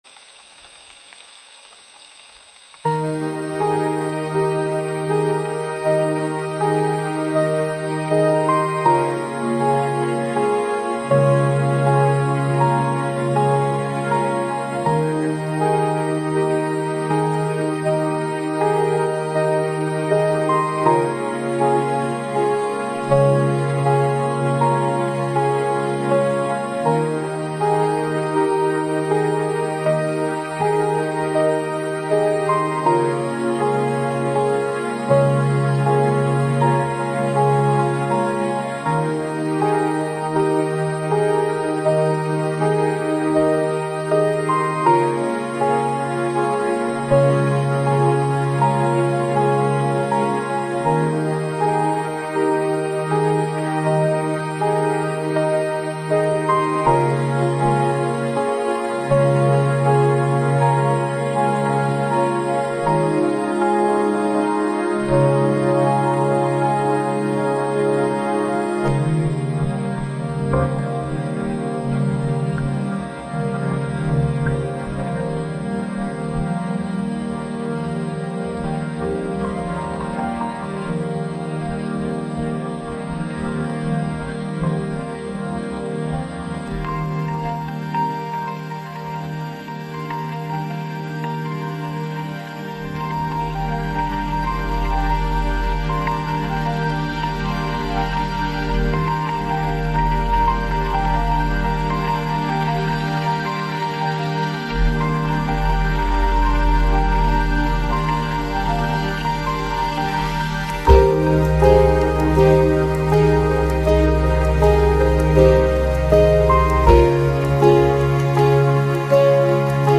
シンセサイザーの穏やかなパッド音が静かに流れ、バックグラウンドには風の音や鳥のさえずりが微かに聞こえる。
エレクトリックピアノの柔らかなメロディが、リラックスした雰囲気をさらに引き立てる。
音楽は次第に静かになり、再び穏やかなシンセサイザーのパッド音が流れ出す。
バックグラウンドには控えめなパーカッションが入り、穏やかなテンポを維持します。